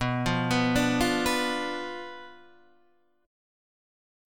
Bdim chord {7 8 9 7 6 7} chord